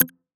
check-off.wav